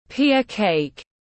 Bánh Pía tiếng anh gọi là Pia cake, phiên âm tiếng anh đọc là /pia keɪk/
Pia cake /pia keɪk/